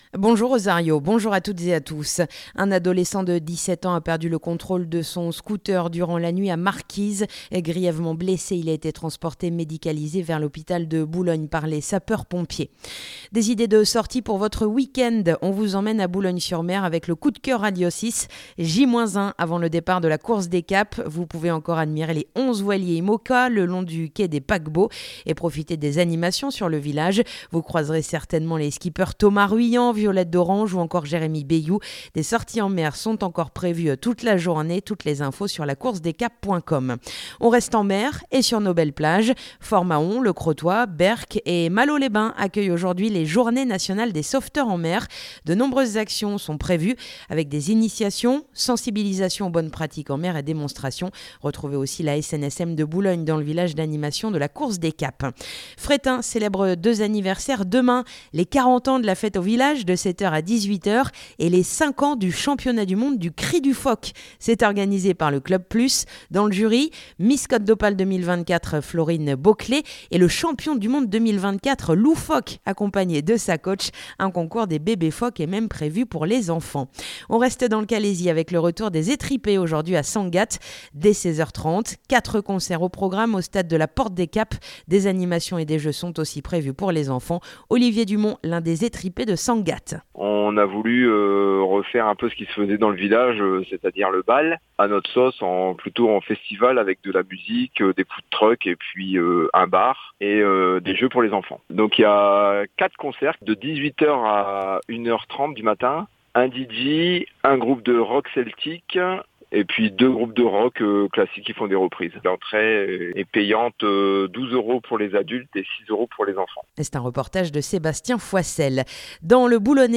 Le journal du samedi 28 juin